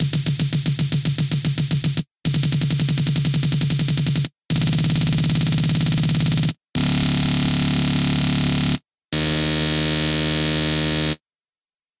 As you shorten the loop you will begin to hear the repeated snare hits blur into what sounds like a single tone.